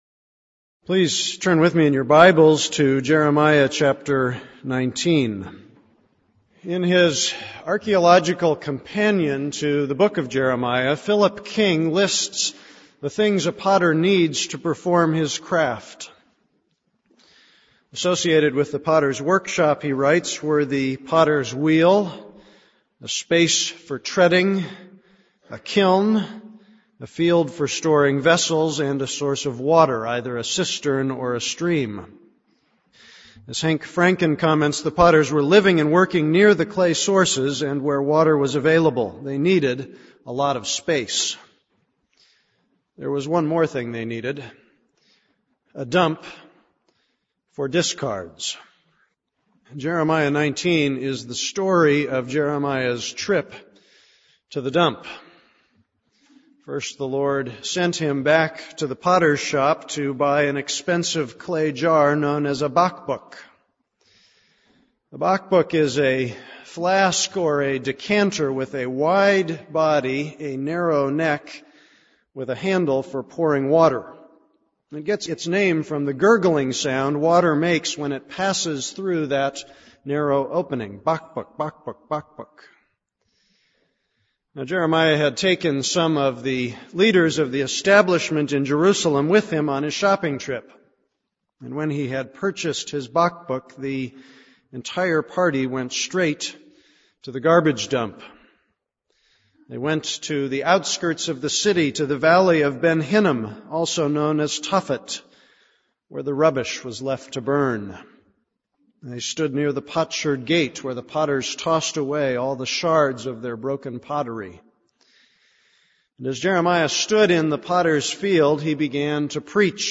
This is a sermon on Jeremiah 19:1-15.